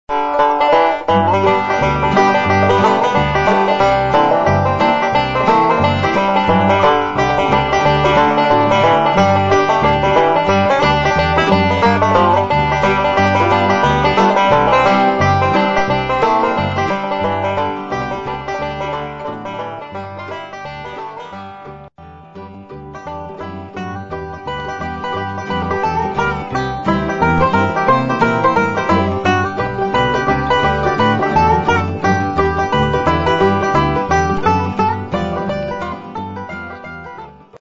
5-String Banjo .mp3 Samples
5-String Banjo Samples - Intermediate Level